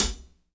-16  HAT 6-R.wav